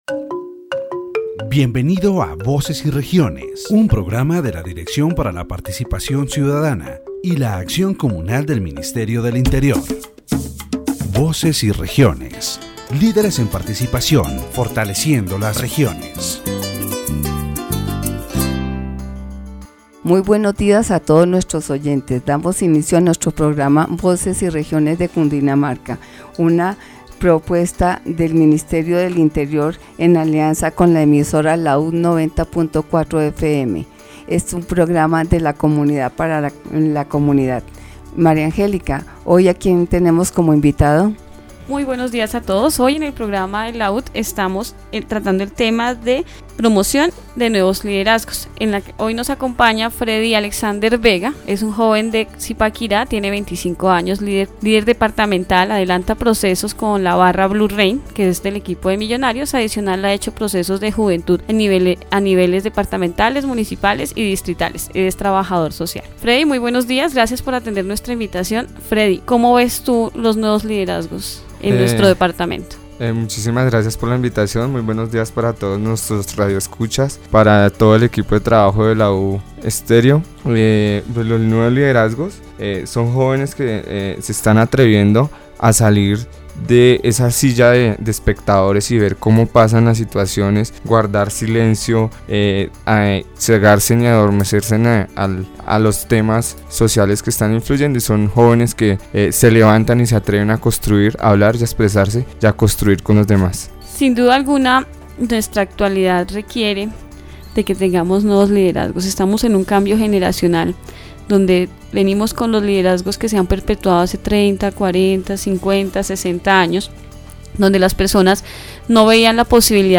The radio program "Voices and Regions" of the Directorate for Citizen Participation and Communal Action of the Ministry of the Interior focuses on the promotion of new leadership in the Department of Cundinamarca.